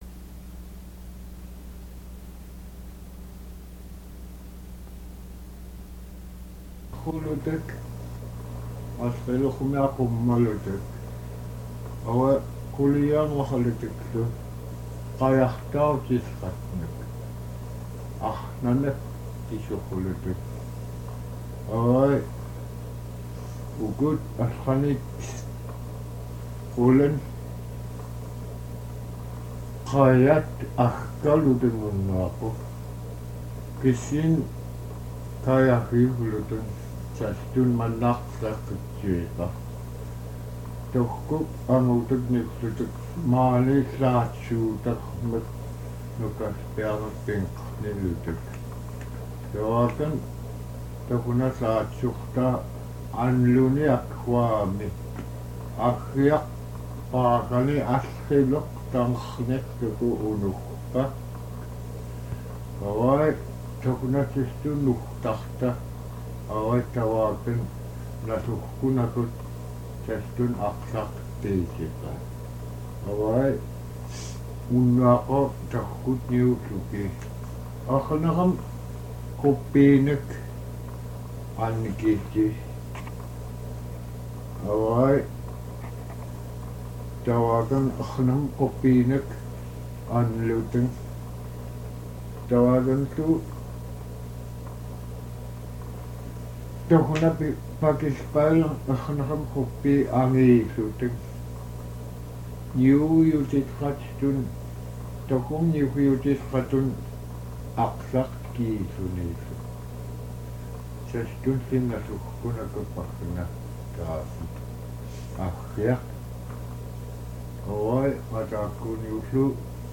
conversation in Alutiiq Description
copy 2=good copy 1=poor
Kodiak, Alaska